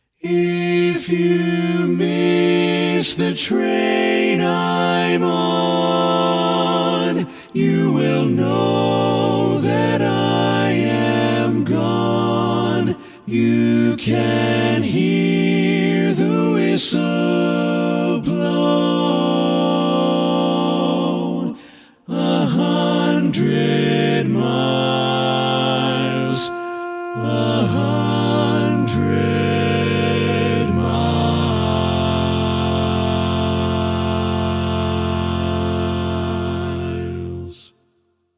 Key written in: F Major
How many parts: 4
Type: Barbershop
All Parts mix:
Learning tracks sung by